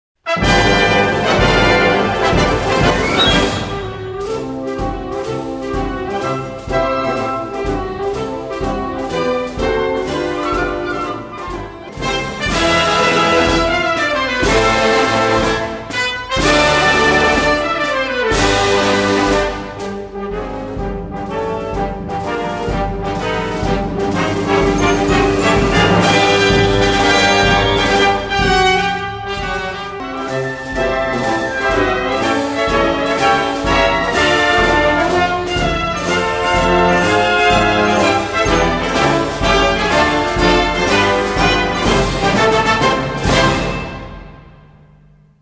難易度 分類 並足１２３ 時間 ２分１５秒
編成内容 大太鼓、中太鼓、小太鼓、シンバル、トリオ 作成No２０７